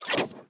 Cybernetic Technology Affirmation 5.wav